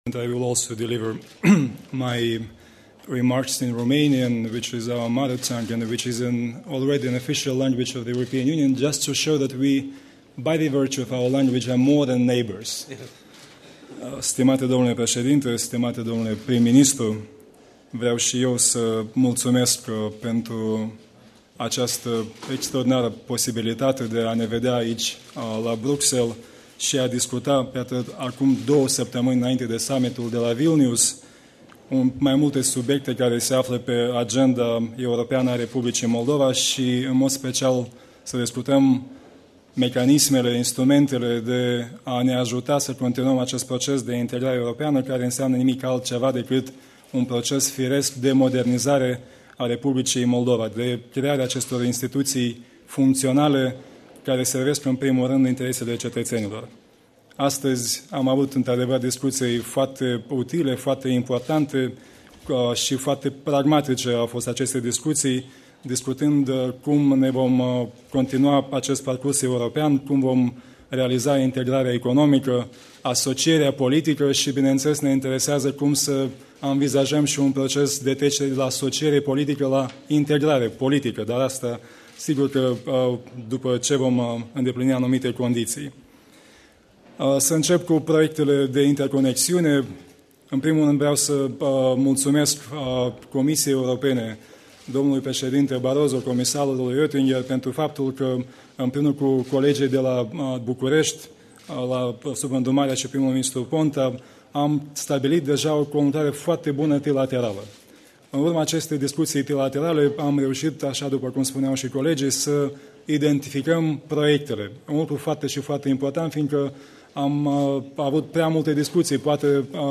La conferința de presă de astăzi, de la Bruxelles
Declarația premierului Iurie Leancă la Bruxelles